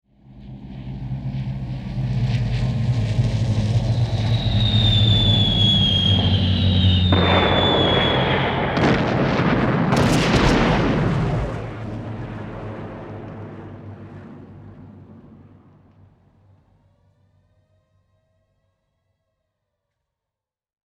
Âm thanh tiếng Máy Bay ném Bom